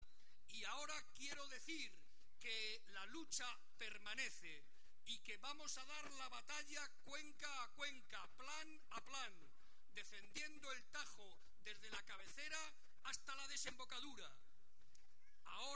Barreda, que hoy participó en la concentración que se celebró en Talavera de la Reina (Toledo) en defensa del río Tajo, intervino ante las más de 15.000 personas que asistieron para recordar que siempre estará dispuesto, con la cabeza bien alta, “a defender nuestros intereses diciendo lo mismo en Toledo que en Madrid, en las Cortes regionales que en el Congreso de los Diputados, aquí y en Murcia, aquí y en Valencia”.